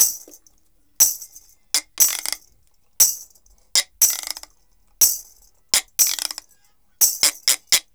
124-PERC2.wav